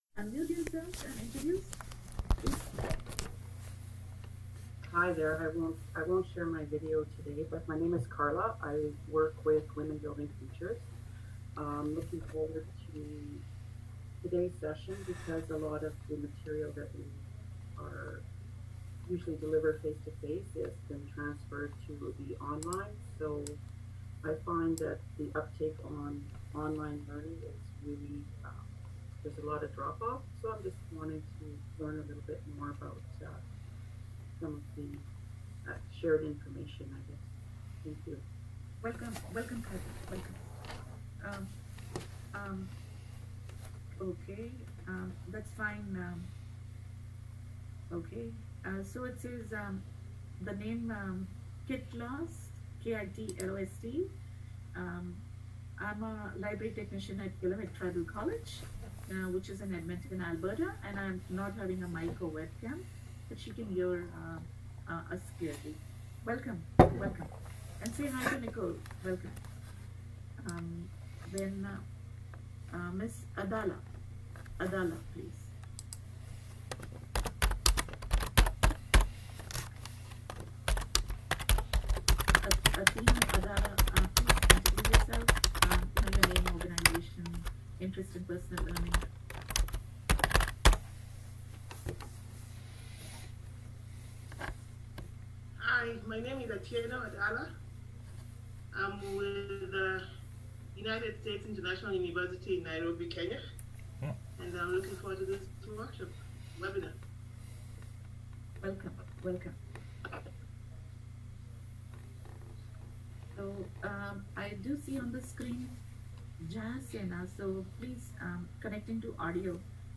(Old style) [ Slides ] [ PDF ] [ Audio ] (New Style) [] Continuing Education, Maskwacis Cultural College, Online, via Zoom, Class, Aug 31, 2020.